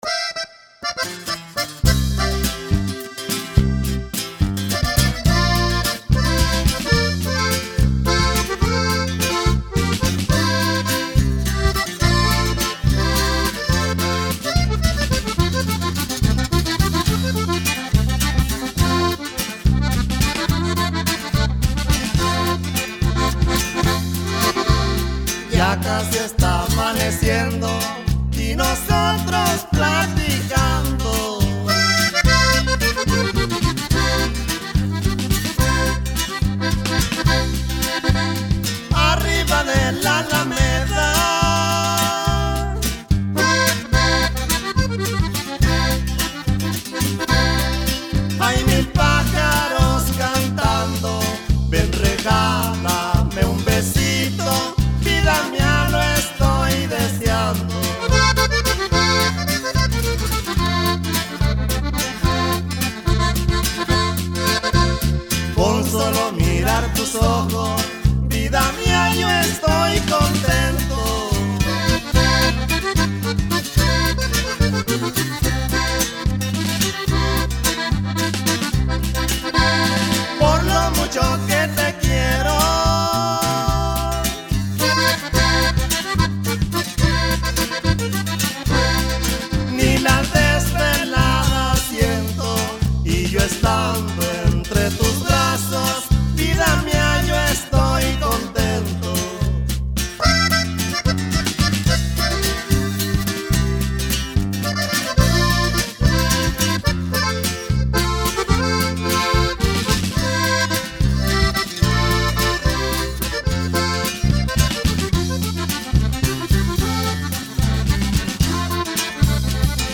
con un estilo alegre y ranchero
grabado en estudios Constelacion de Culiacan